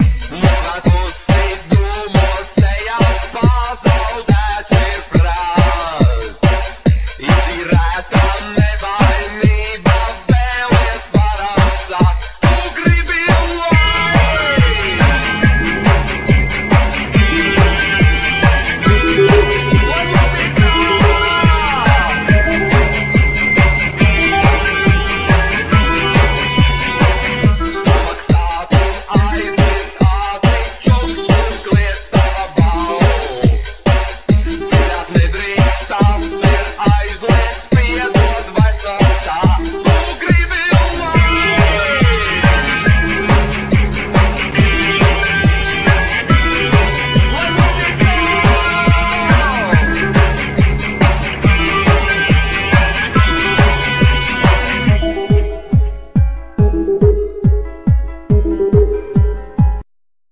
Vocals,Guitar
Bass,Guitar
Drums
Programming